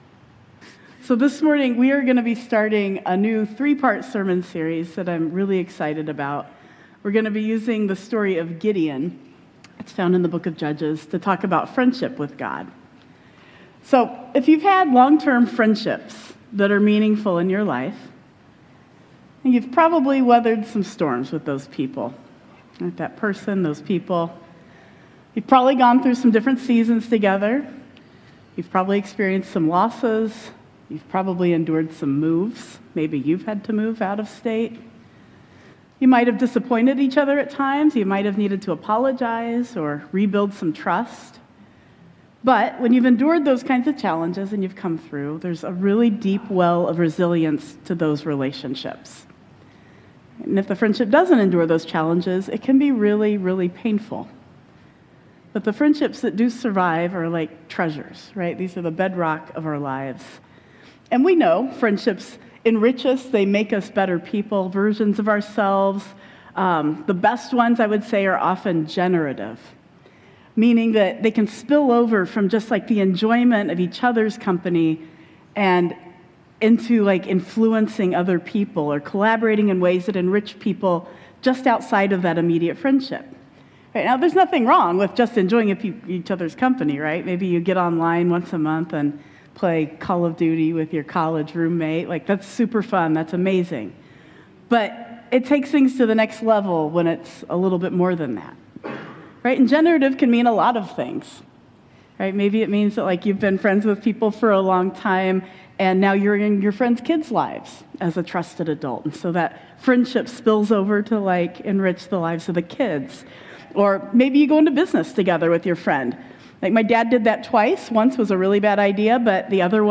We open a three-part sermon series on friendship with God using the story of Gideon in Judges 6–7. Who does God say you are? What does it mean to build trust with God?